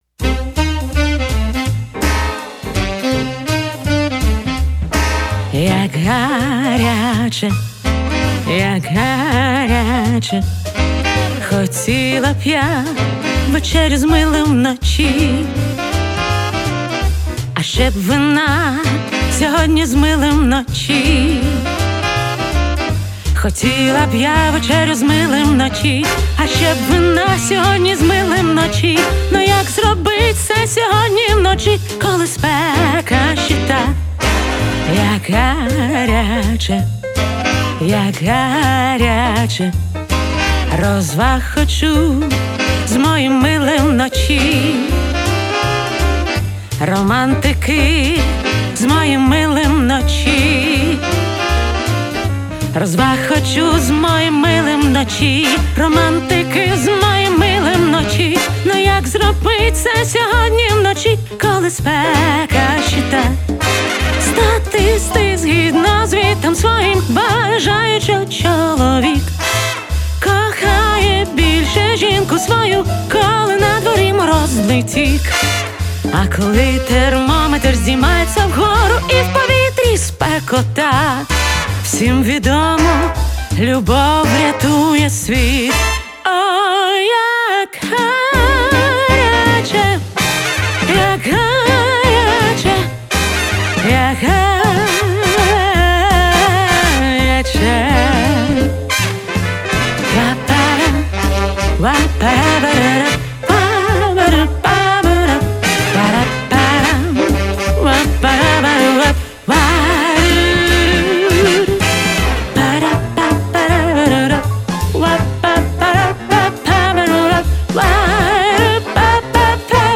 Більше часу в студії шло саме на український спів!
))) Дякую, то є джаз biggrin